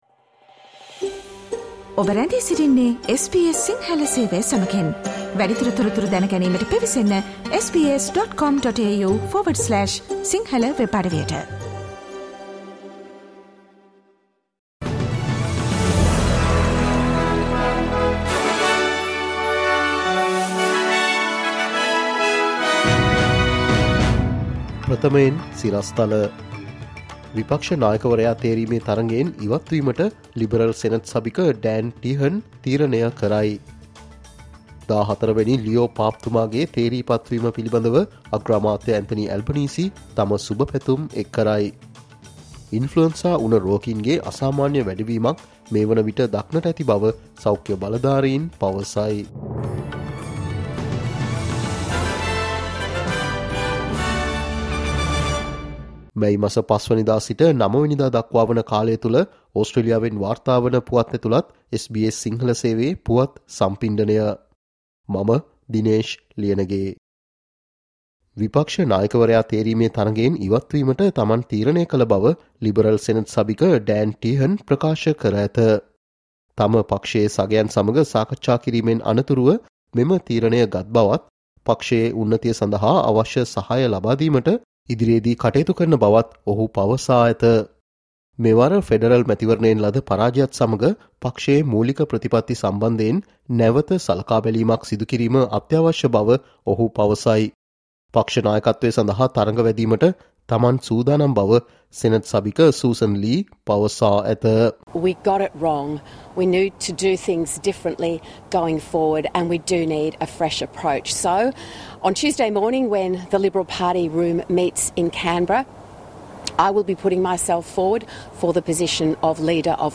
'මේ සතියේ ඔස්ට්‍රේලියාව': SBS සිංහල ගෙන එන සතියේ ඕස්ට්‍රේලියානු පුවත් සම්පිණ්ඩනය, මැයි 05 - මැයි 09